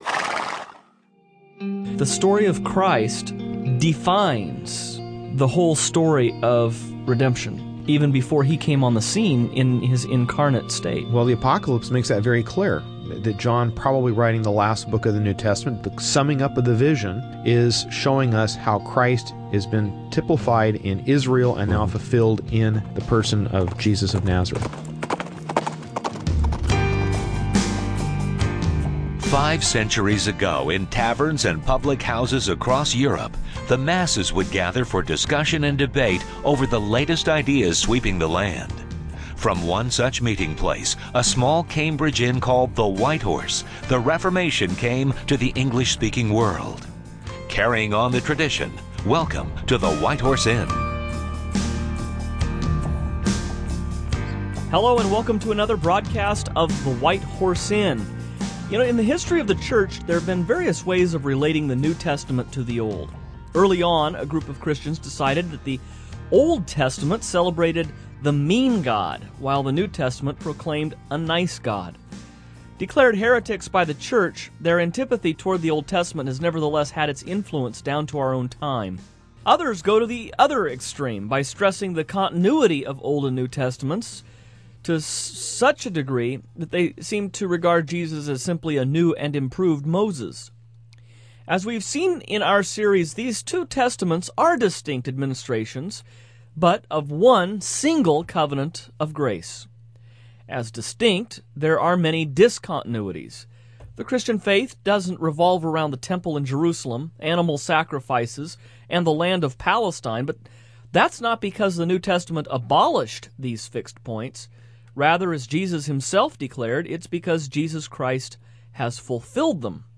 But why did God become human? What was his ultimate mission? Tune in to this edition of the White Horse Inn as the hosts discuss the birth of Christ and the opening of his public ministry.